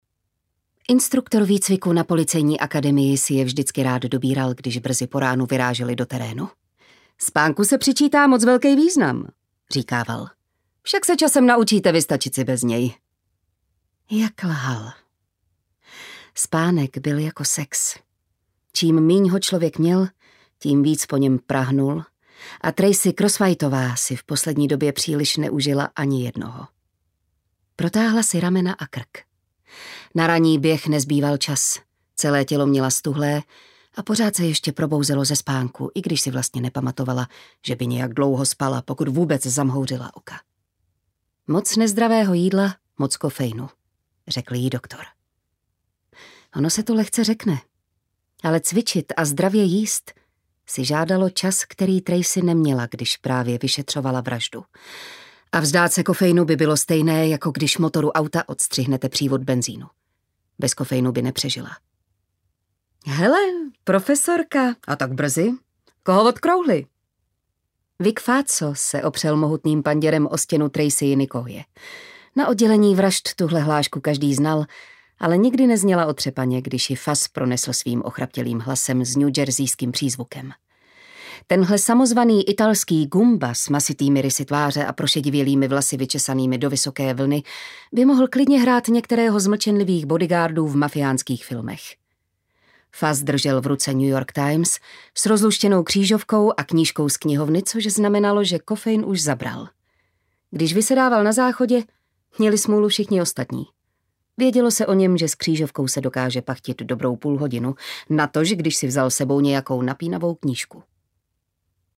Hrob mé sestry audiokniha
Ukázka z knihy